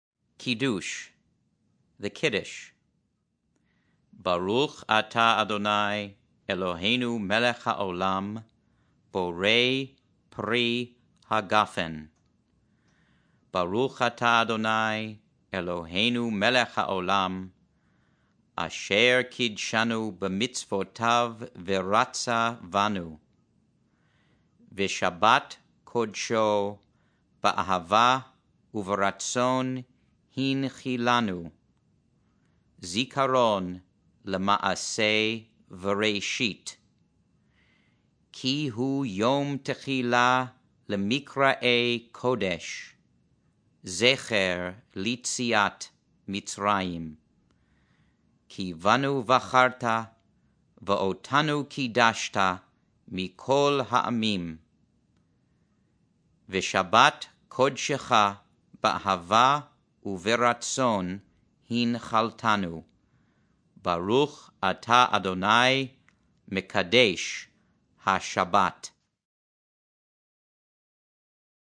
R5 Friday Night Kiddush Read.mp3